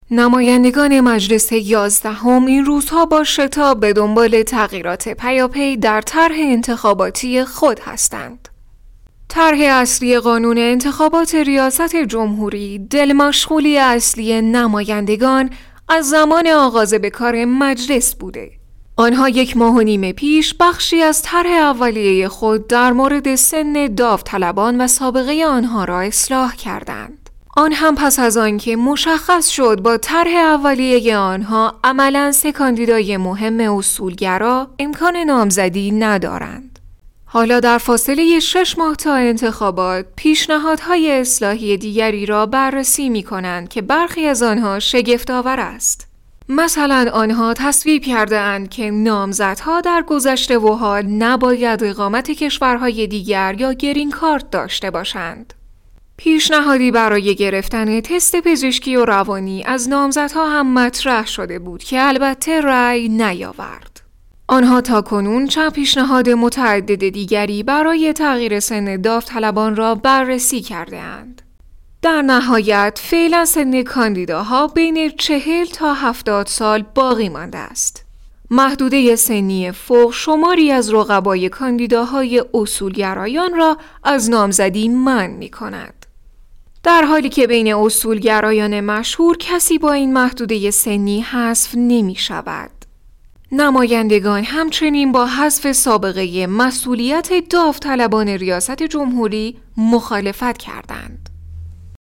新闻主播【端庄大气】